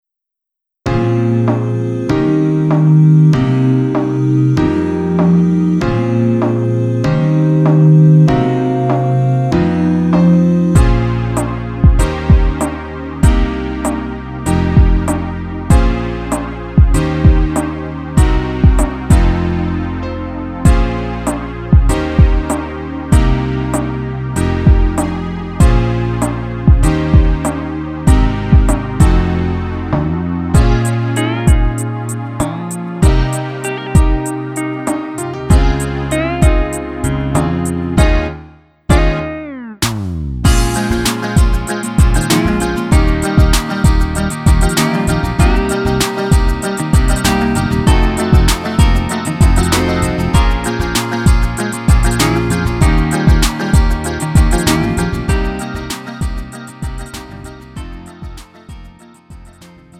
음정 -1키 3:35
장르 가요 구분 Lite MR